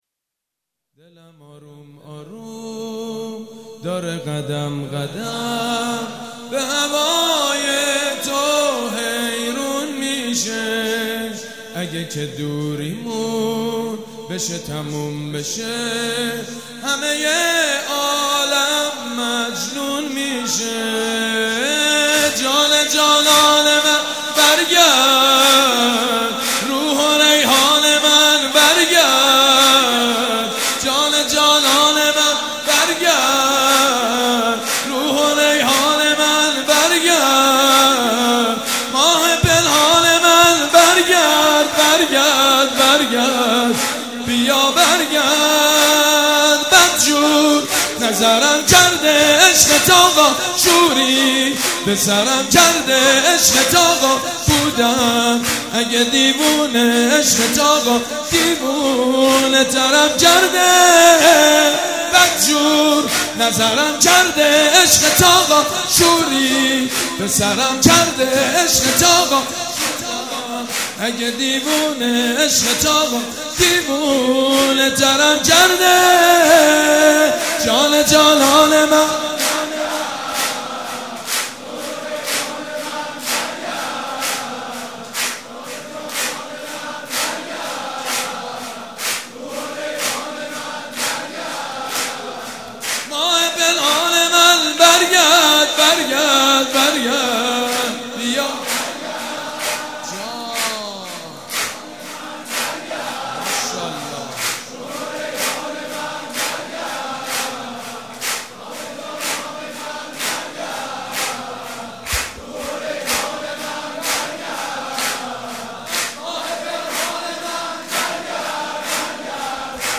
جشن میلاد امام زمان(عج)
سرود
مداح
حاج سید مجید بنی فاطمه
جشن نیمه شعبان